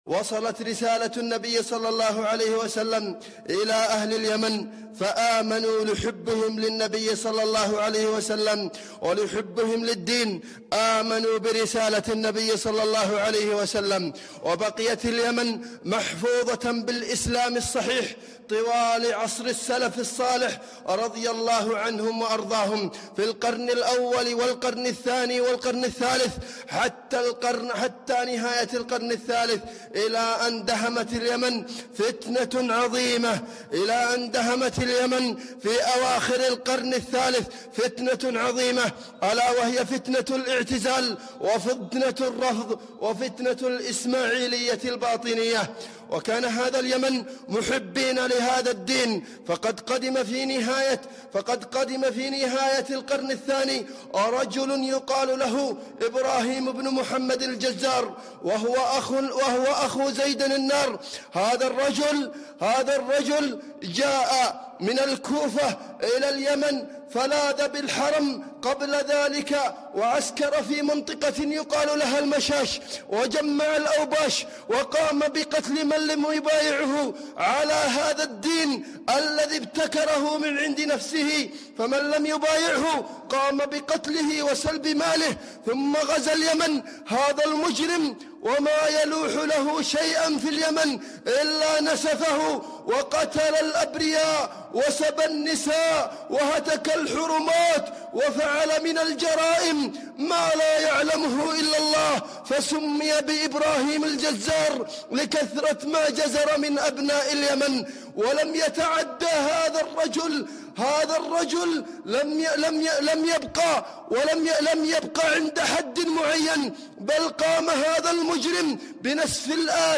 مقتطف من خطبة - تاريخ الحوثيين في عصور الظلام